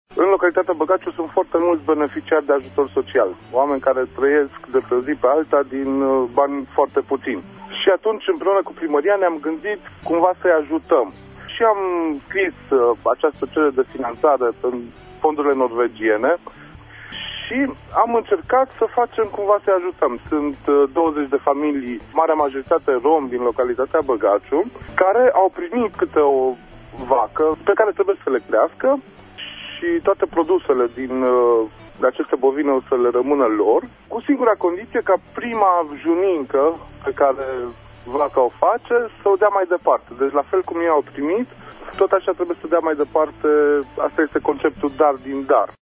invitat astăzi la emisiunea “Pulsul Zilei” ne spune cine sunt beneficiarii şi ce trebuie să facă ei în continuare: